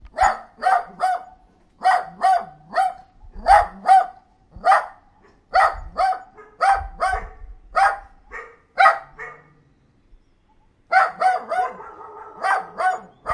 狗 - 声音 - 淘声网 - 免费音效素材资源|视频游戏配乐下载
描述：狗吠，用Zoom H4n Pro内置麦克风录制。
标签： 树皮 宠物 叫声 动物
声道立体声